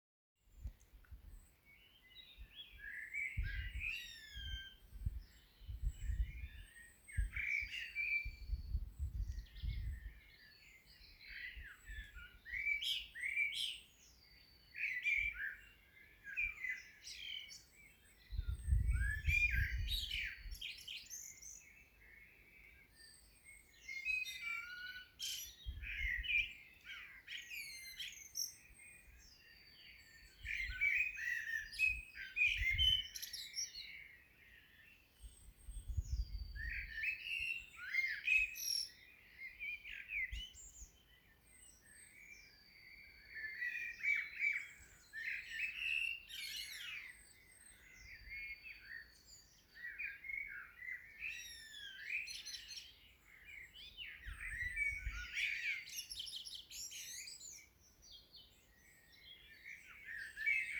Serenade vor dem Fenster – Vogelgezwitscher in Frühling
Die Aufnahmen sind ziemlich leise, nehmt lieber Kopfhörer…
Zwischen halb sechs und sechs, irgendwann in März aufgenommen.